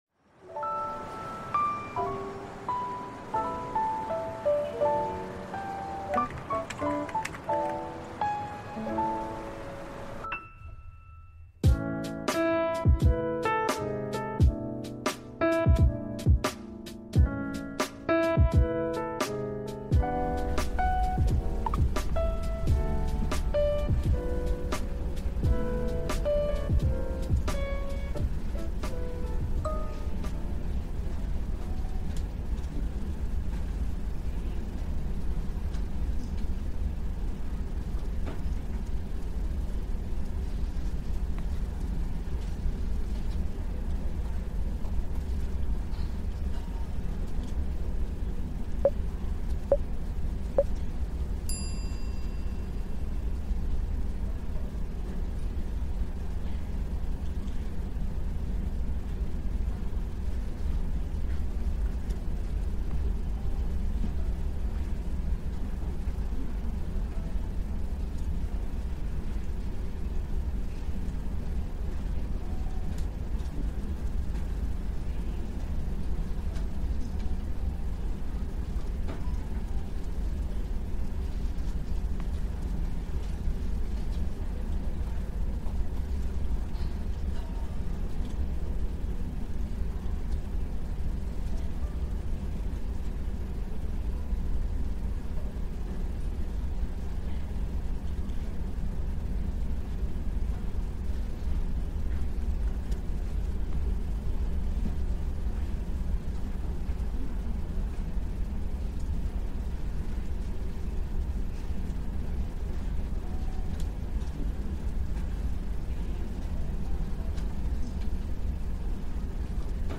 Prague Naturelle : Pluie Pour Étude